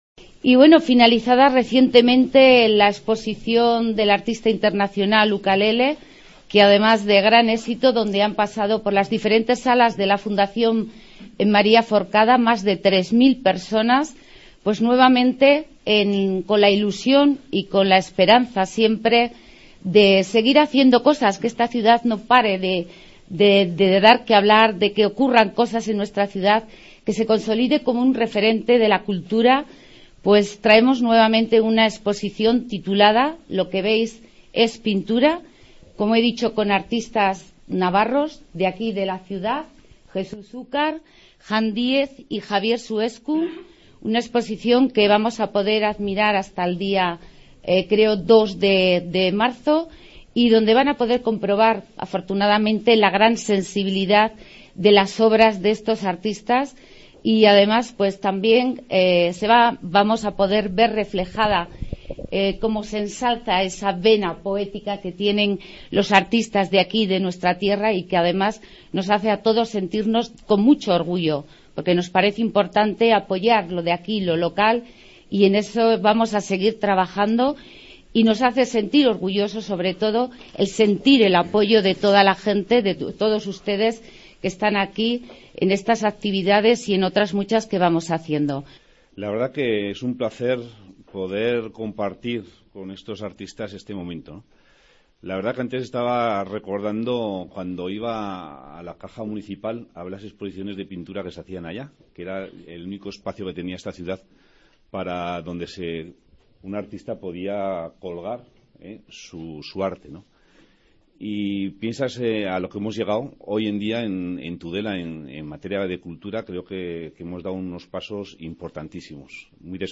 AUDIO: Reportaje sobre la exposición de pintura